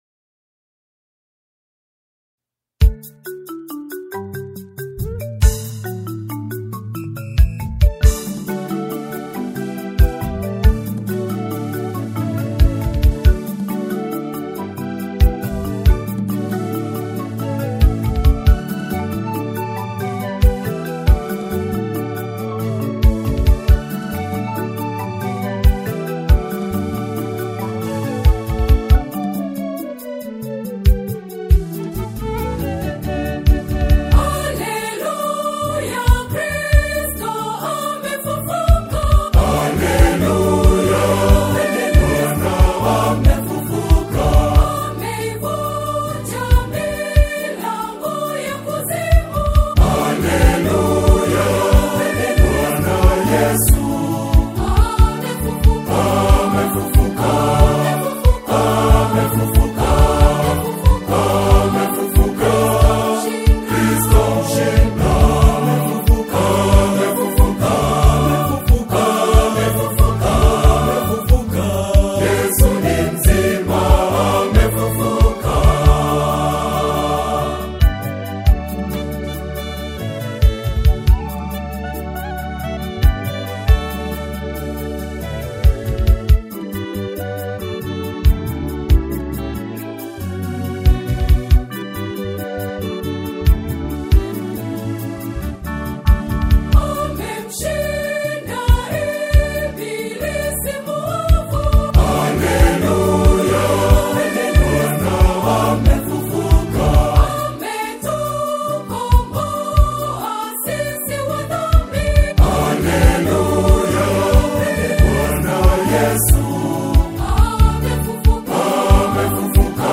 a majestic and celebratory liturgical anthem